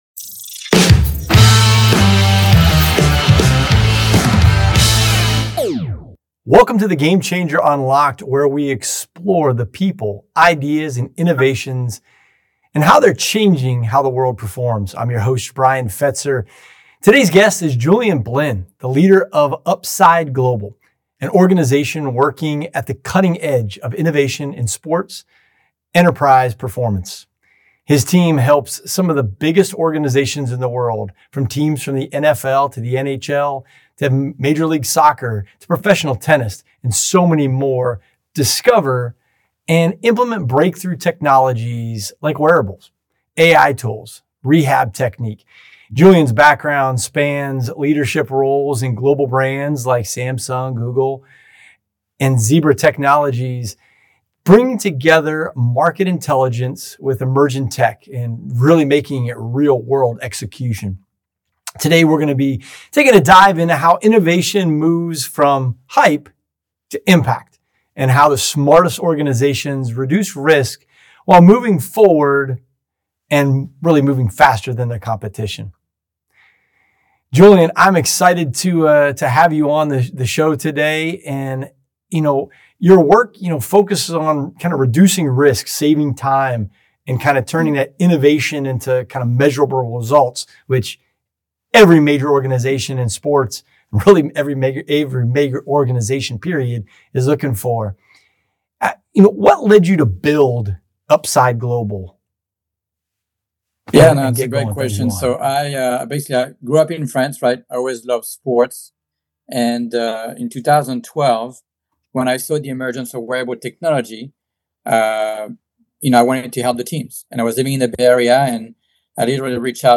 Discover how cutting-edge technology is reshaping sports performance, leadership strategy, and business innovation. In this episode, a global sports-tech expert reveals how elite teams across major leagues use AI, wearables, recovery tools, and data analytics to reduce injuries, improve sleep, and maximize results.